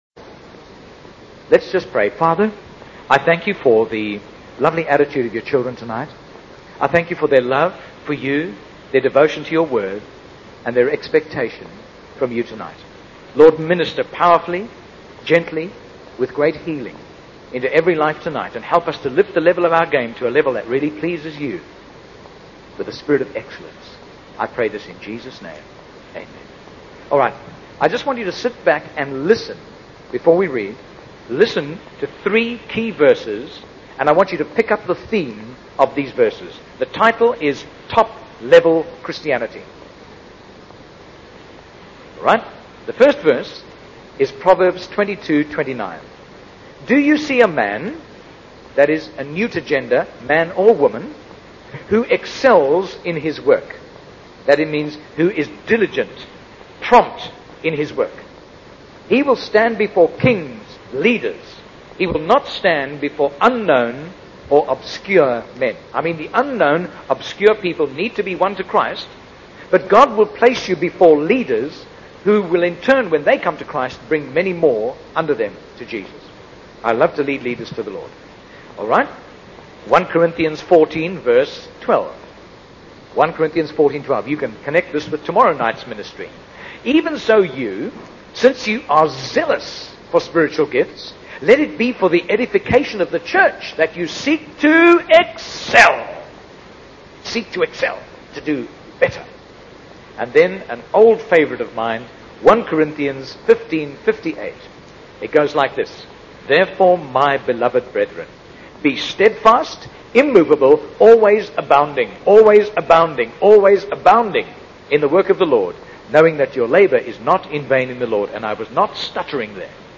He's very high-powered, full of divine energy, and also a motivational speaker for churches.